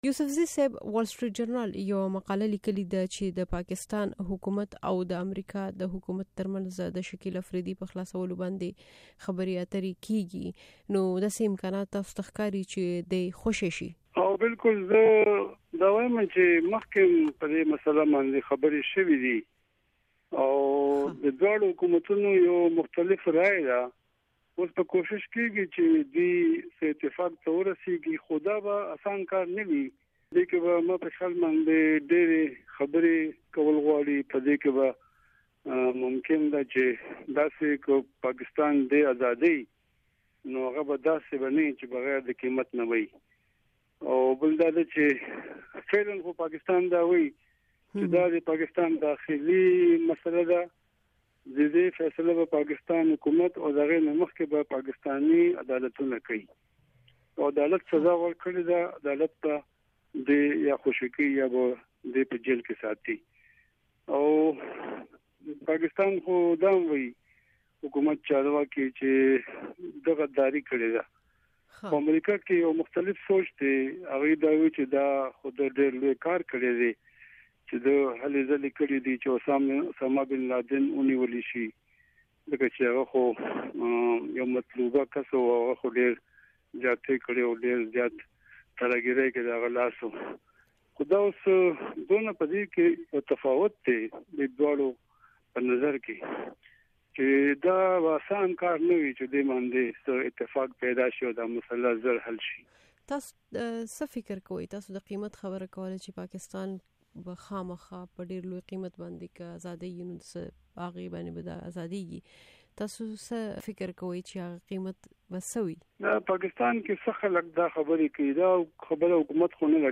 مرکې
د رحیم الله یوسفزي سره مرکه